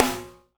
-DEJASNR 1-L.wav